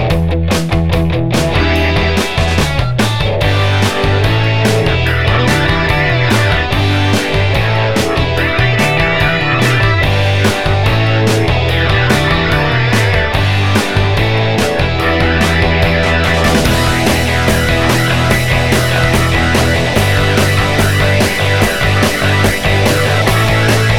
Minus Main Guitar Rock 3:24 Buy £1.50